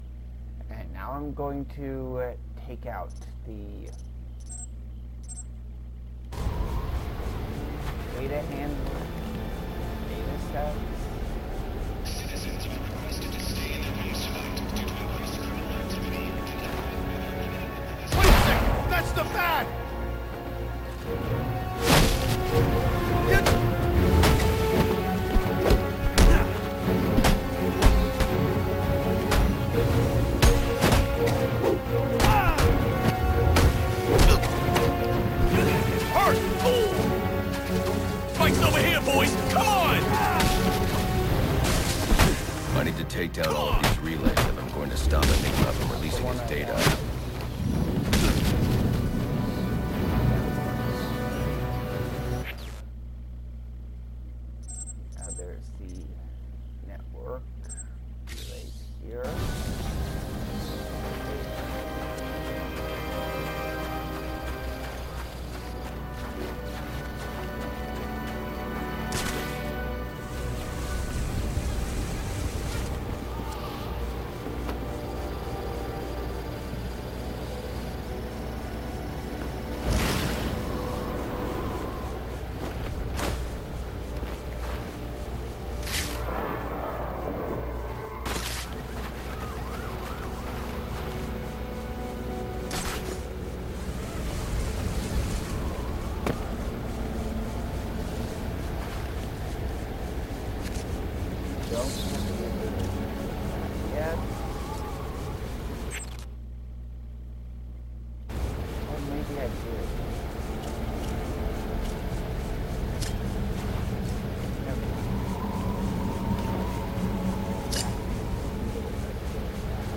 I play Batman Arkham Origins with commentary